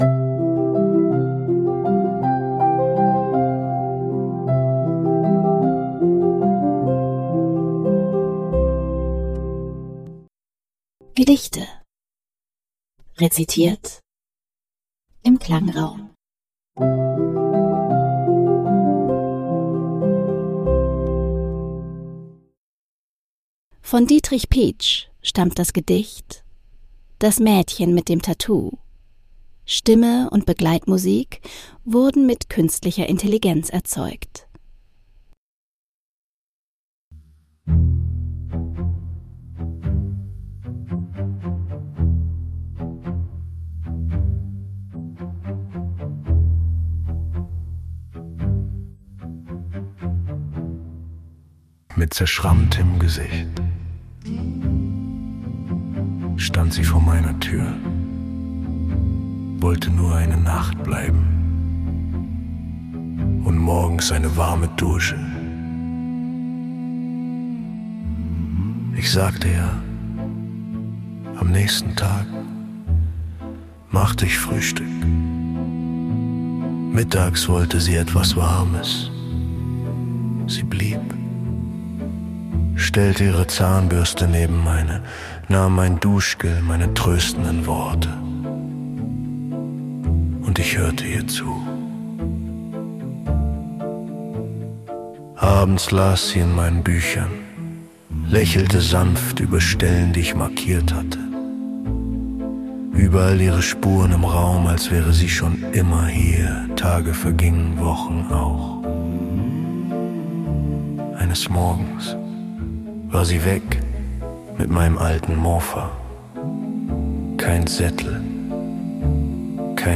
Stimme und Begleitmusik wurden mit
Künstlicher Intelligenz erzeugt. 2026 GoHi (Podcast) - Kontakt: